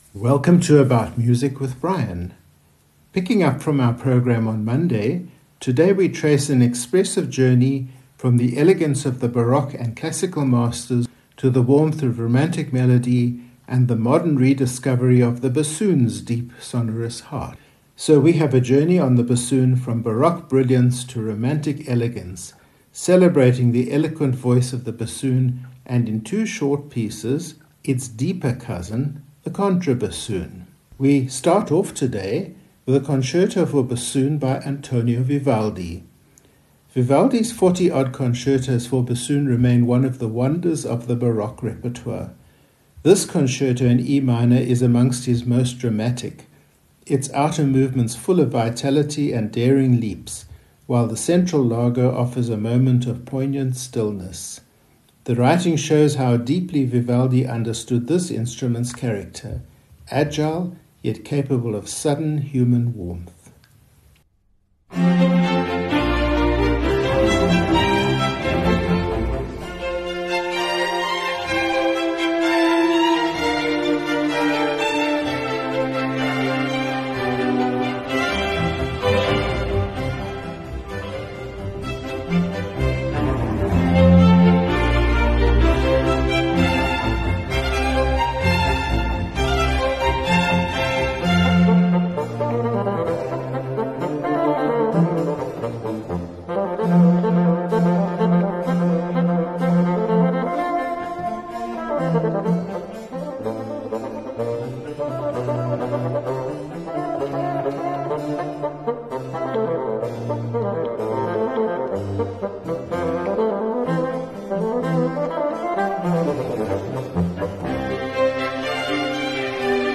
Sundowner Programme 32 Into the Deep – Bassoon and Contrabassoon – - Gay SA Radio
Our programme today traces an expressive journey from the elgance of the Baroque and Classical Masters to the warmth of Romantic melody and the modern rediscovery of the bassoon’s deep sonorous heart.
So we have a journey from Baroque brilliance to Romantic elegance, celebrating the eloquent voice of the bassoon and in two short pieces, its deeper cousin, the contrabassoon.
From the brightness of Vivaldi’s Venice to the twilight lyricism of Strauss, this programme celebrates the full expressive range of the double-reed family — a true dialogue into the deep.